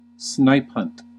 Ääntäminen
US : IPA : /ˈsnaɪpˌhʌnt/